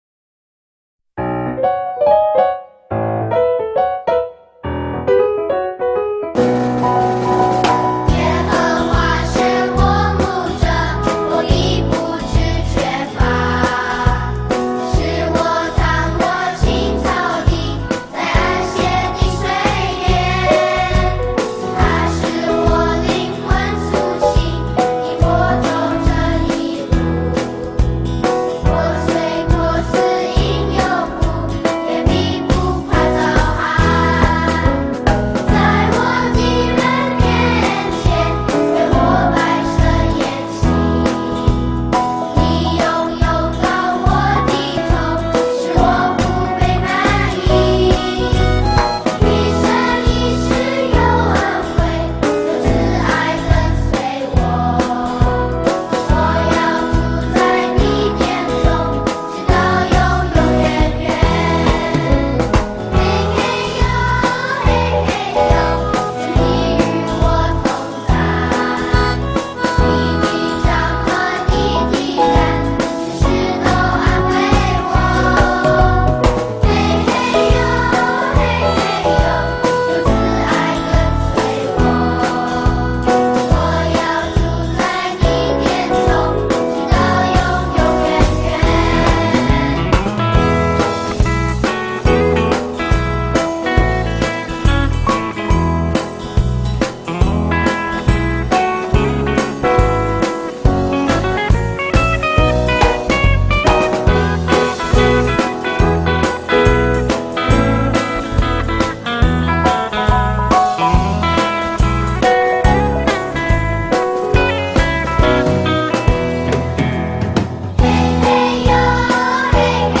儿童赞美诗 | 耶和华是我牧者